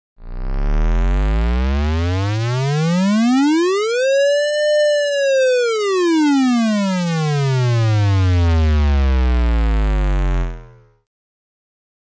kanonskogel_a_cd35
kanonskogel_a_cd35.mp3